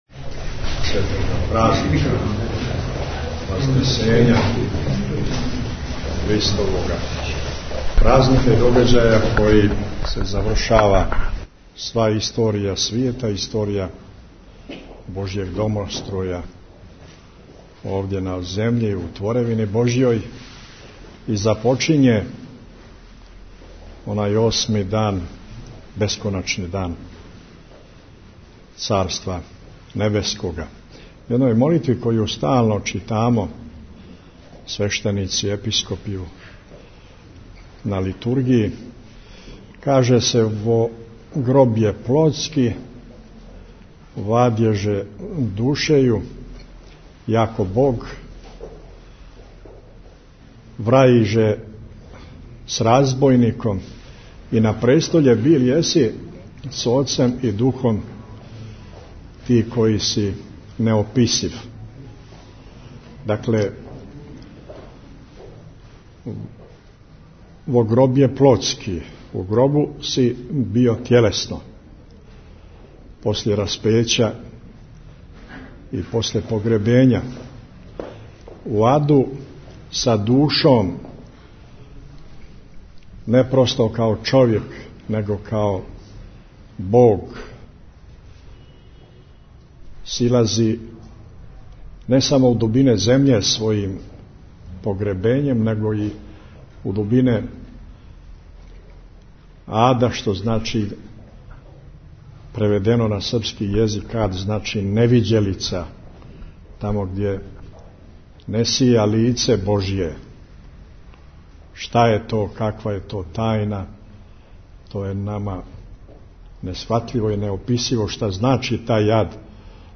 Митрополитова бесједа у навечерје празника Вазнесења Господњег у Подгорици | Радио Светигора
Tagged: Бесједе Наслов: Mitropolit G. Amfilohije Албум: Besjede Година: 2011 Величина: 13:35 минута (2.34 МБ) Формат: MP3 Mono 22kHz 24Kbps (CBR) Бесједа Његовог Високопреосвештенства Архиепископа Цетињског Митрополита Црногорско Приморског Г. Амфилохија изговорена у Вазнесењској цркви при Саборном Храму Христовог Васкрсења у Подгорици, у навечерје храмовне славе, Вазнесења Господњег.